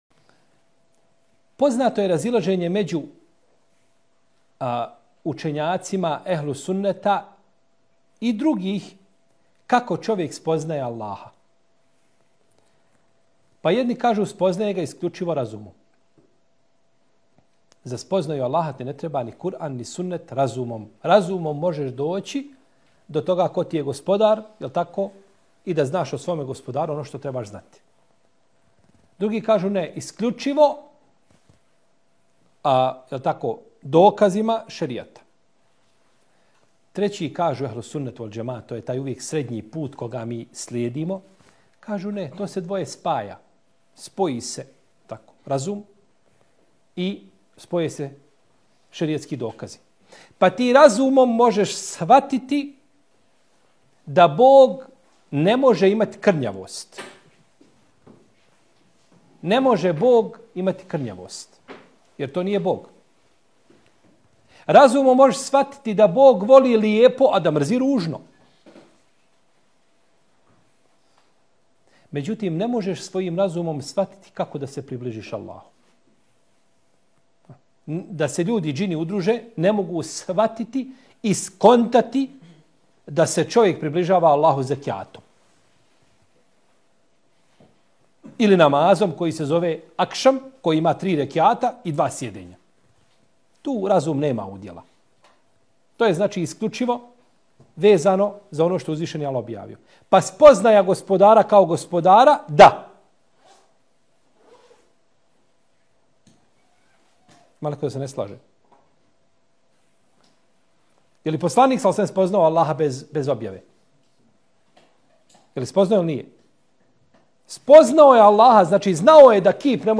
Odgovor iz predavanja